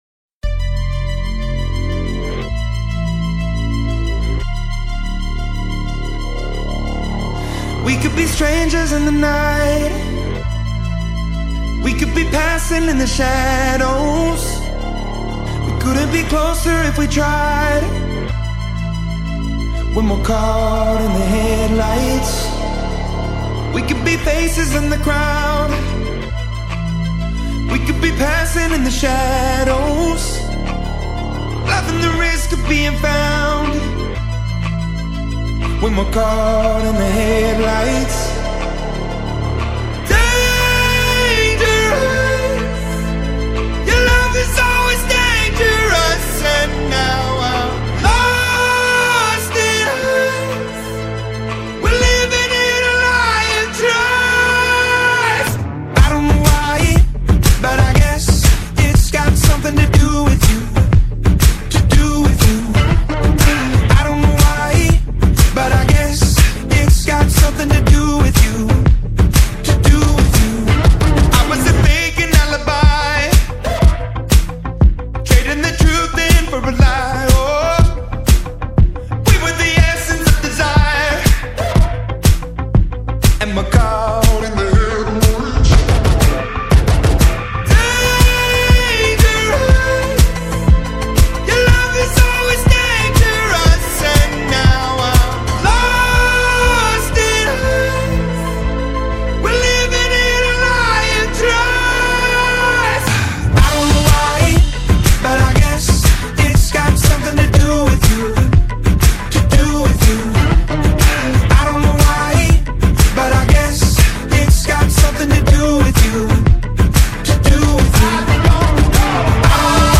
Alternative Rock, Pop Rock